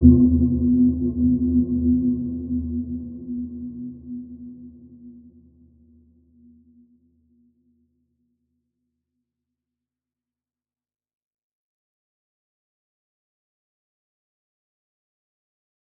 Dark-Soft-Impact-B3-mf.wav